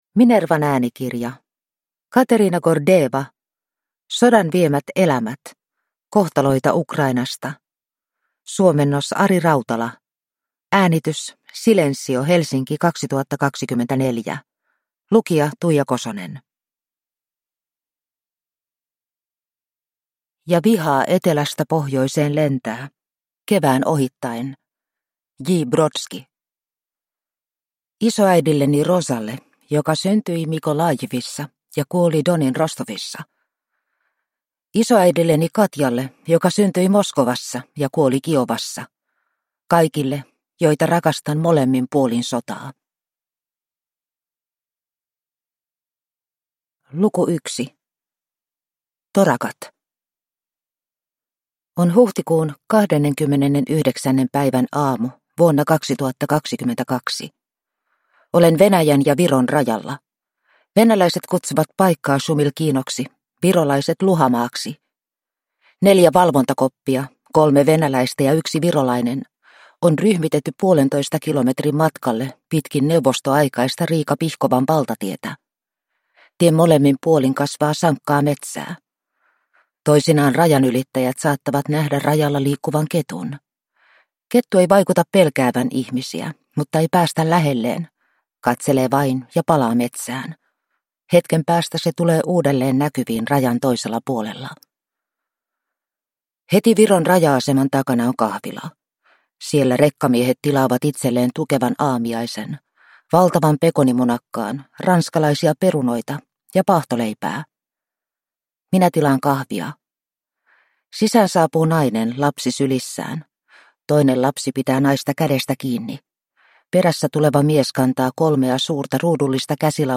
Sodan viemät elämät – Ljudbok